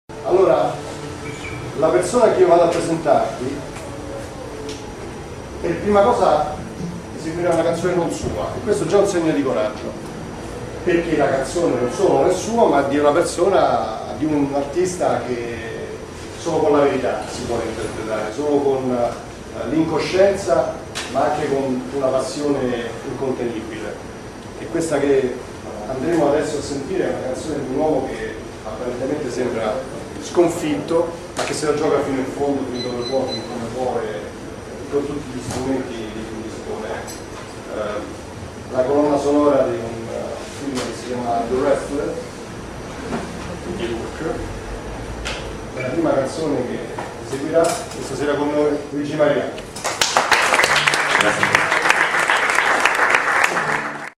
ROMA, libreria caffè "N'importe quoi"